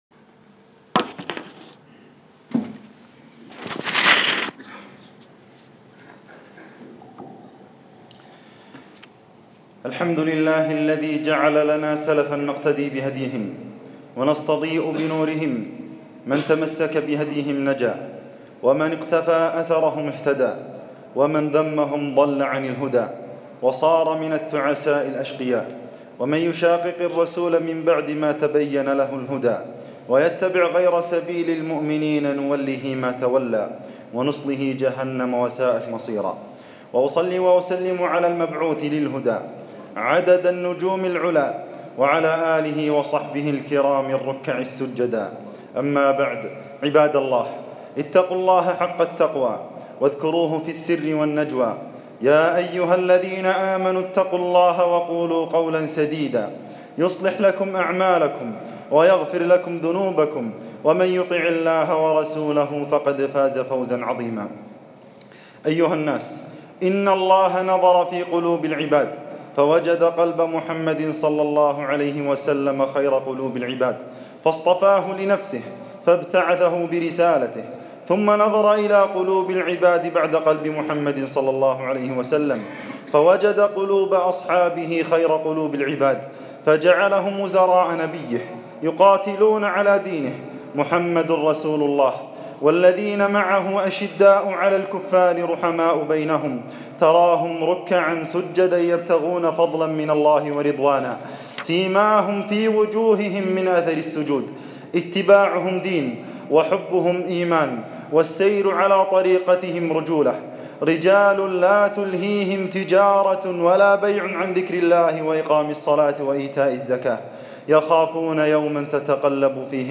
صديق هذه الأمة - خطبة